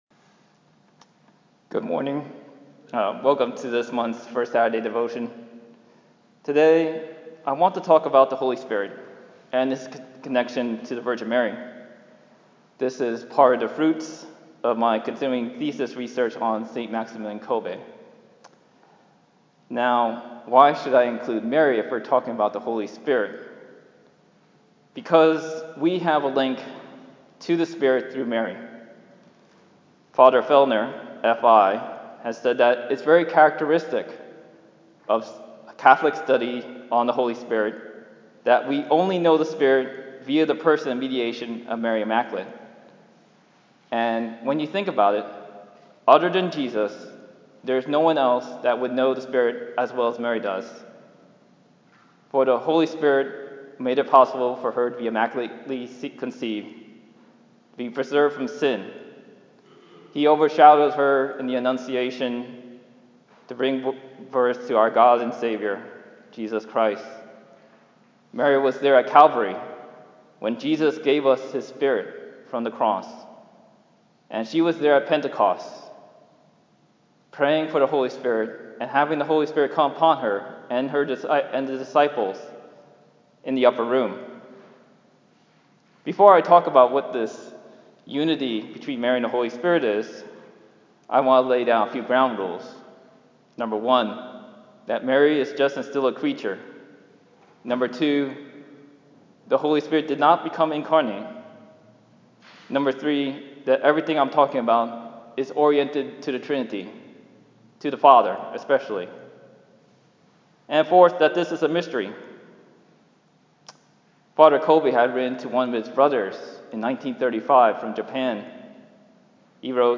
I returned to a familiar topic for my First Saturday talk on April 6, 2019, at St. Clement. Similar to the Faith Friday talk in Denver, I discussed the Holy Spirit and his connection to the Virgin Mary, according to St. Maximilian Kolbe.
The feedback from the audience has been a huge help.